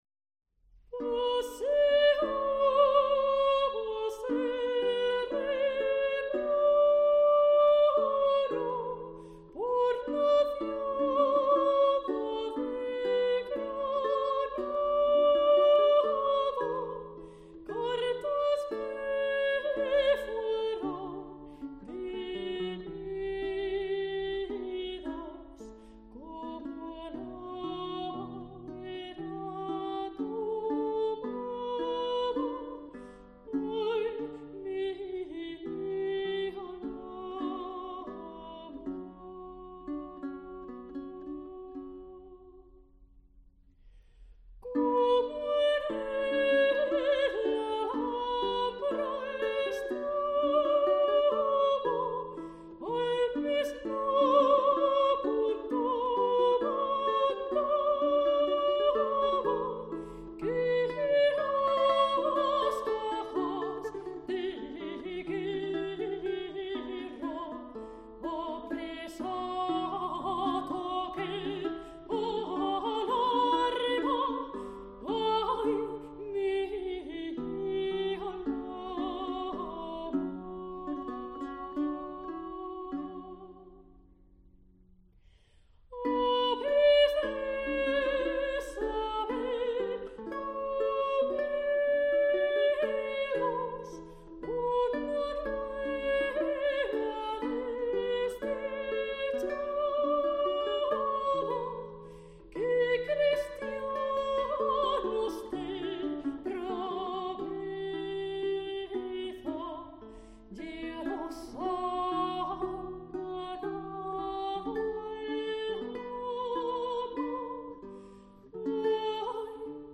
soprano
vihuelista